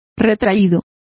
Complete with pronunciation of the translation of withdrawn.